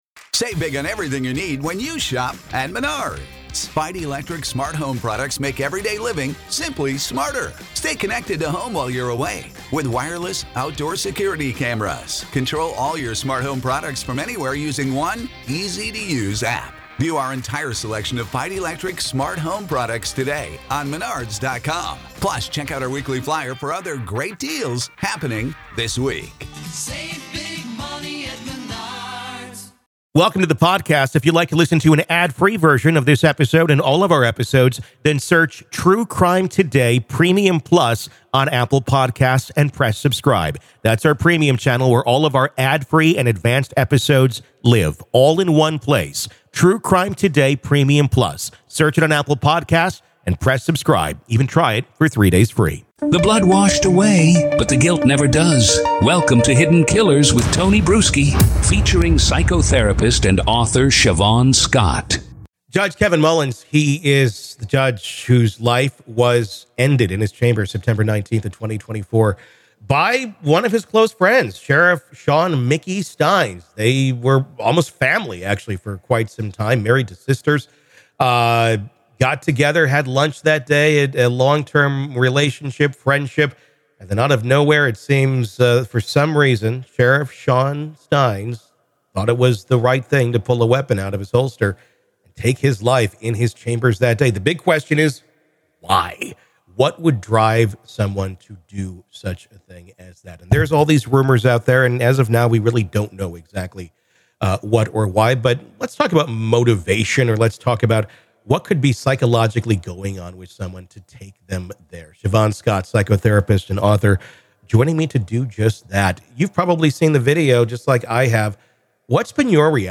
Was there a hidden trigger or a spiraling delusion no one saw coming? The conversation explores how delusional disorders, even rare ones, can surface suddenly and profoundly alter lives.